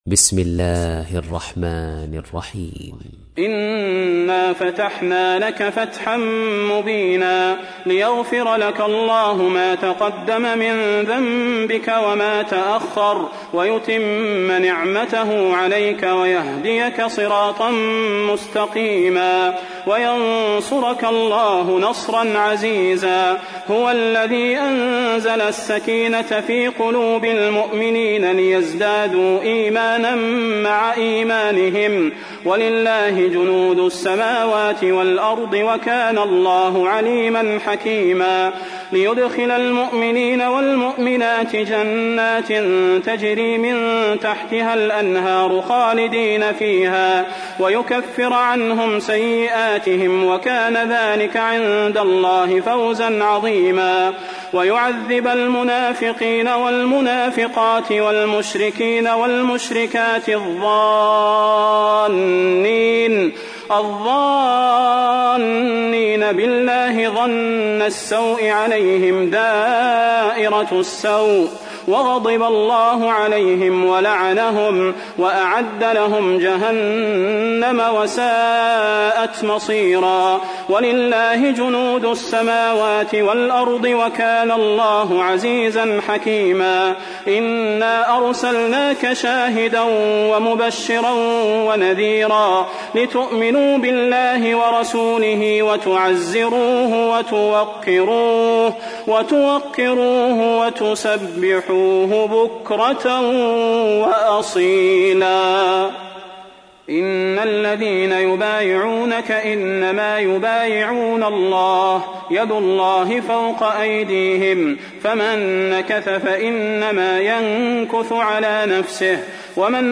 تحميل : 48. سورة الفتح / القارئ صلاح البدير / القرآن الكريم / موقع يا حسين